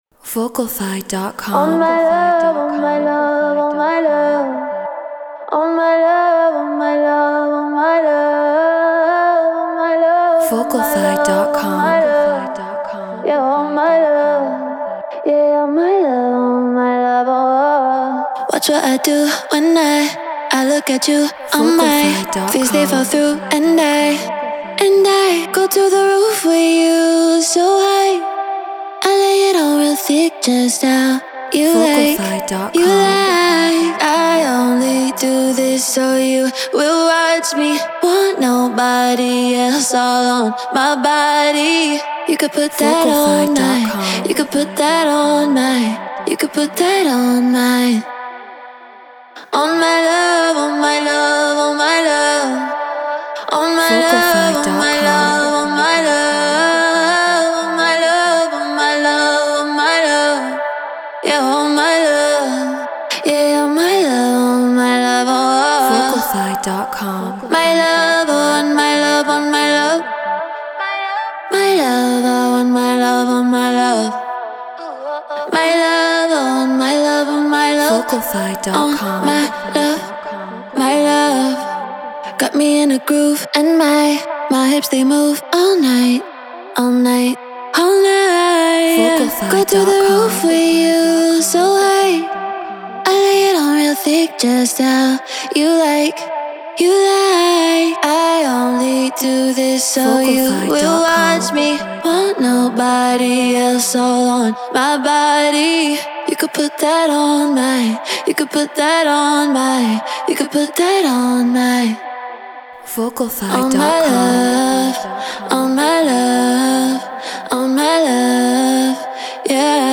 Afro House 120 BPM Amin
Shure SM7B Scarlett 2i2 4th Gen Ableton Live Treated Room